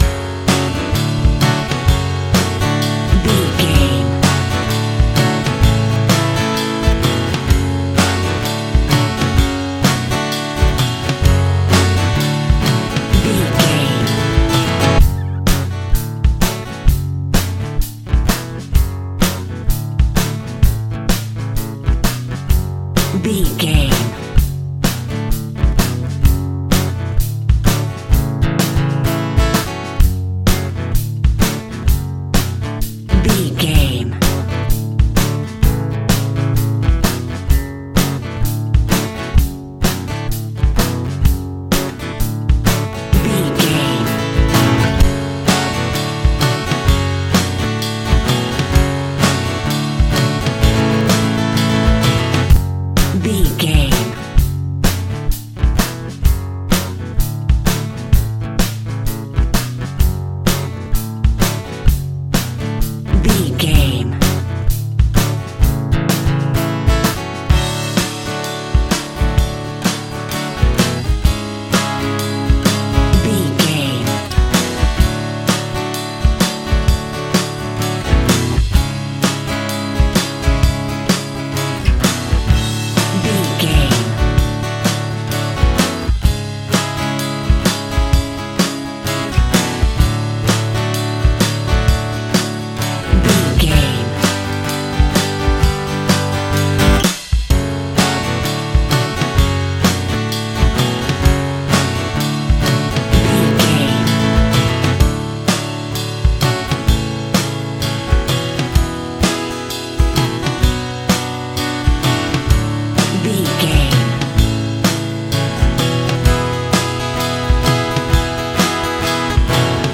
Aeolian/Minor
G♭
groovy
powerful
electric guitar
bass guitar
drums
organ